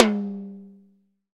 TOM TOM 98.wav